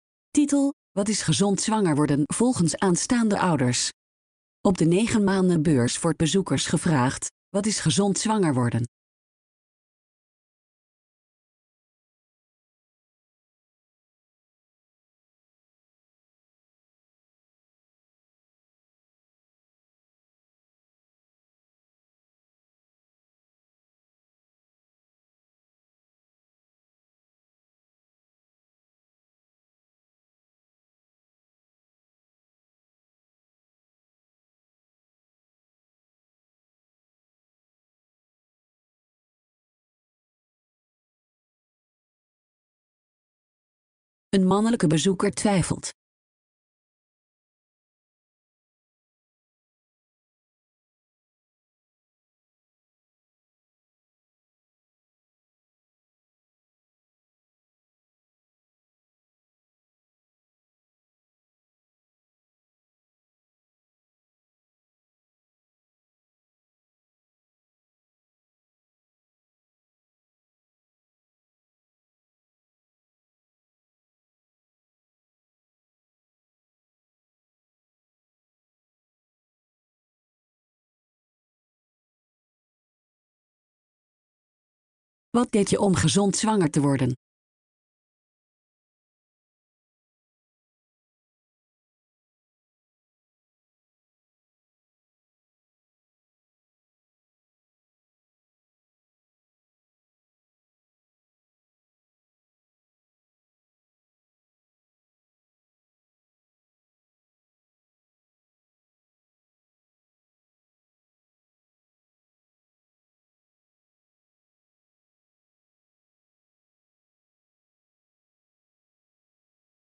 Dit was een productie van het ministerie van VWS